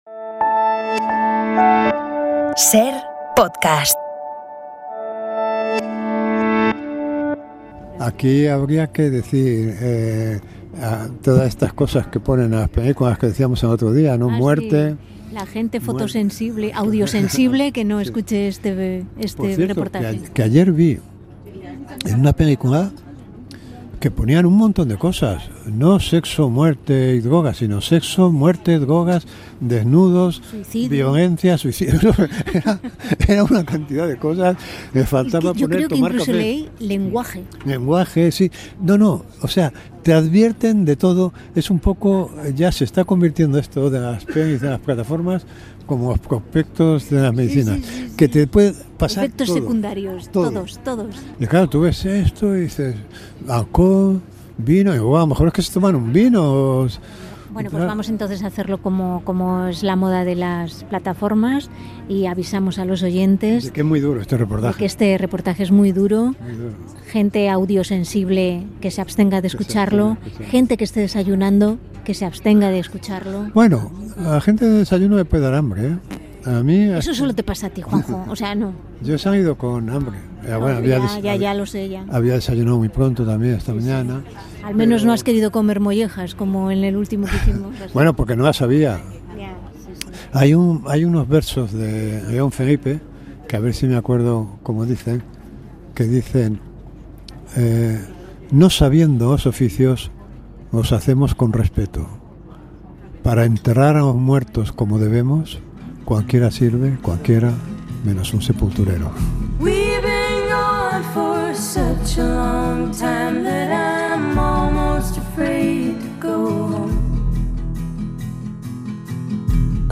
pasan una mañana en una clase de embalsamamiento con los alumnos del Instituto Español Funerario